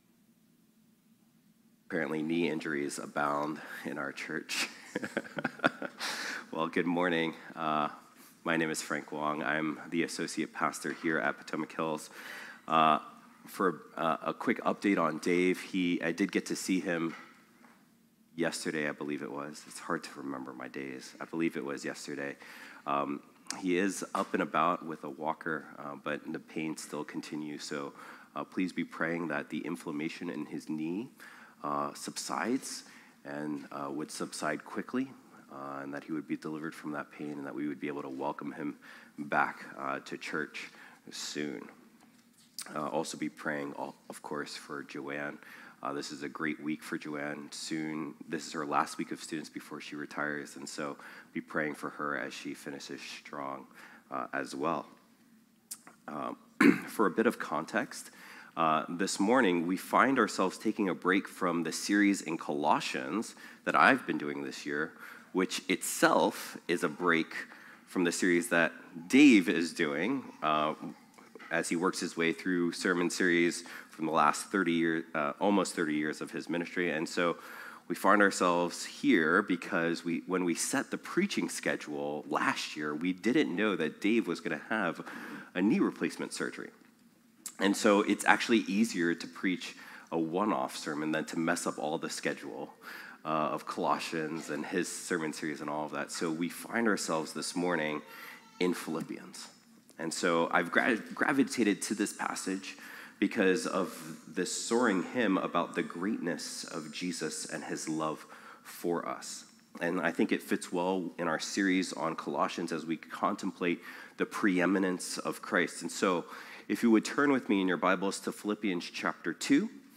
phpc-worship-service-6-8-25.mp3